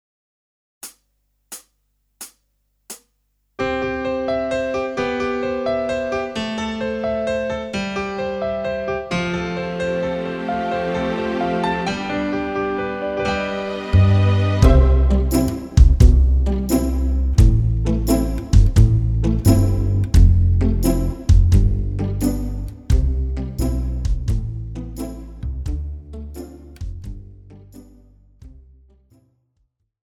Žánr: Pop
BPM: 88
Key: C
MP3 ukázka